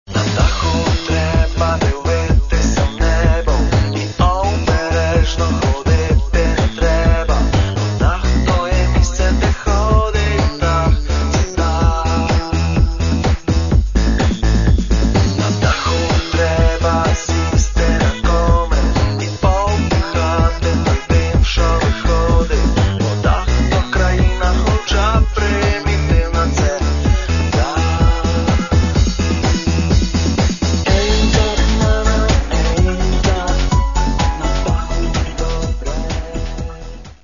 dance remix